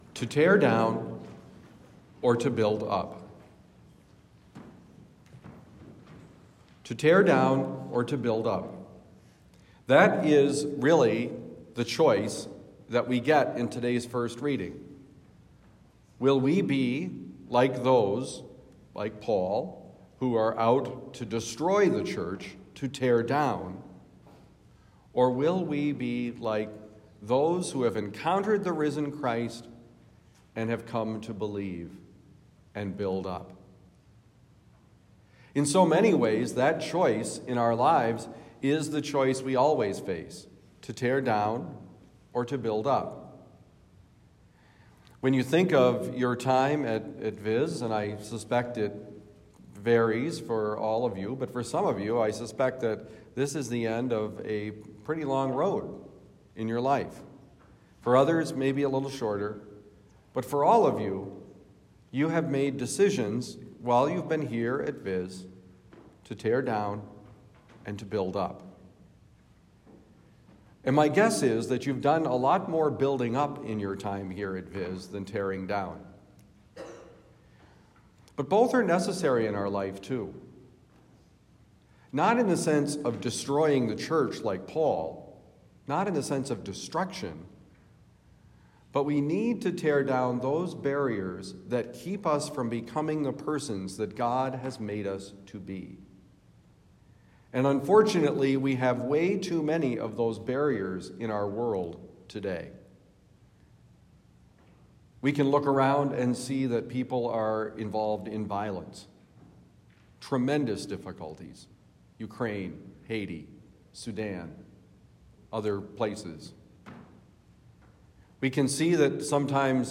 To Tear Down or to Build Up: Homily for Wednesday, April 26, 2023
Given at Visitation Academy, Town and Country, Missouri.